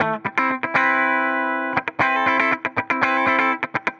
Index of /musicradar/dusty-funk-samples/Guitar/120bpm
DF_BPupTele_120-A.wav